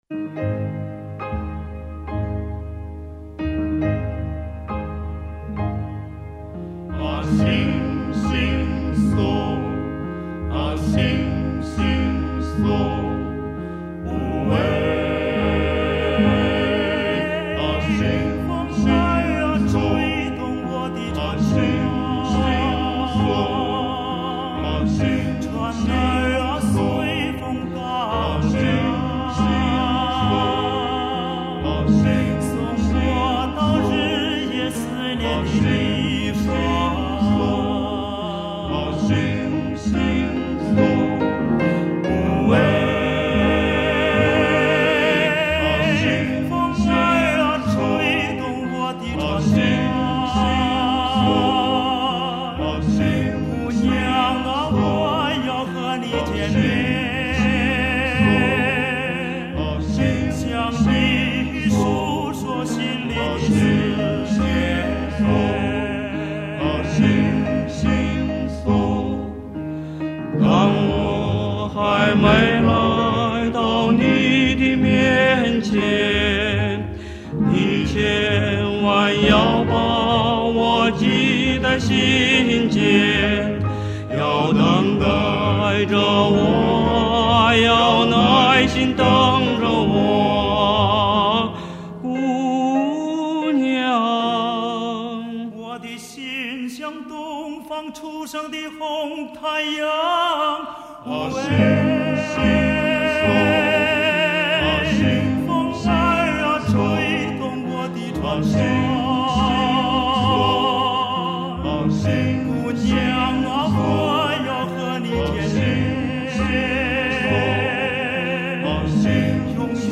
男声四重唱